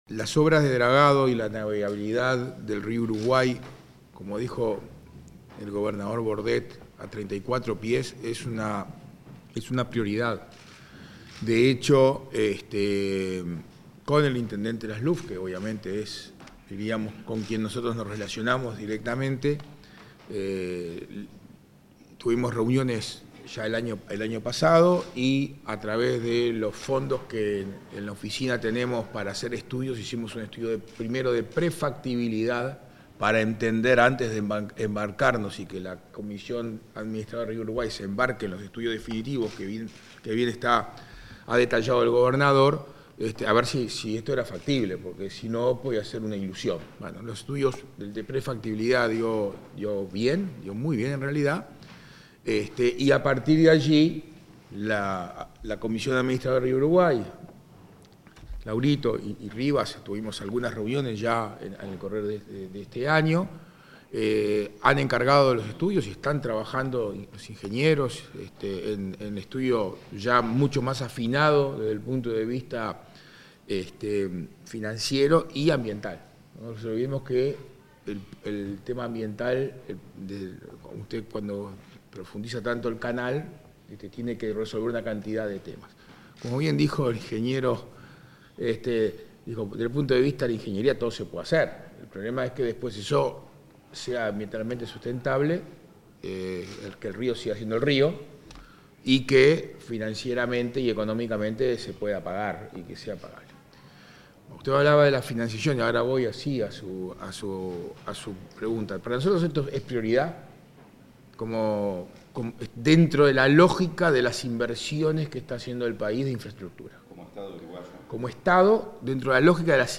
Conferencia de prensa sobre navegabilidad y dragado del río Uruguay
Conferencia de prensa sobre navegabilidad y dragado del río Uruguay 05/09/2023 Compartir Facebook X Copiar enlace WhatsApp LinkedIn Tras la reunión entre el presidente de la Oficina de Planeamiento y Presupuesto (OPP), Isaac Alfie, y el intendente de Río Negro, Omar Lafluf, este 5 de setiembre, se realizó una conferencia de prensa para informar sobre lo tratado acerca de navegabilidad y dragado del río Uruguay.